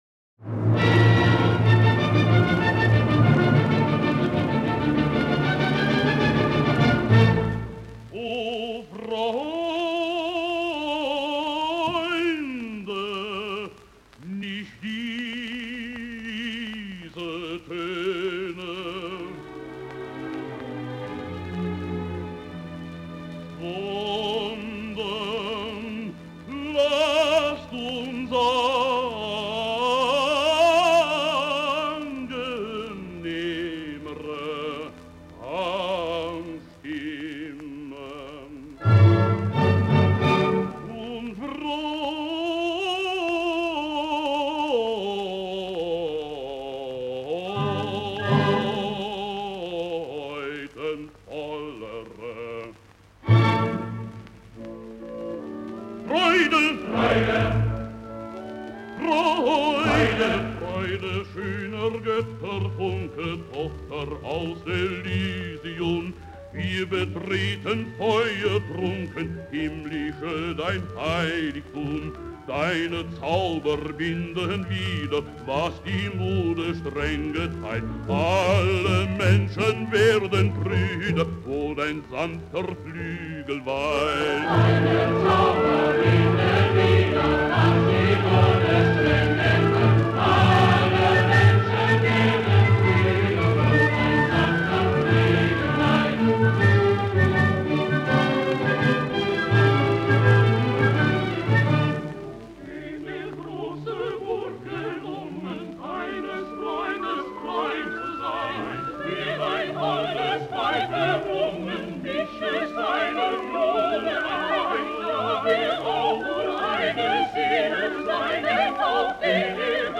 3. JOSEF HERRMANN (Baritone)
Sächsische Staatskopelle
cond. by Karl Böhm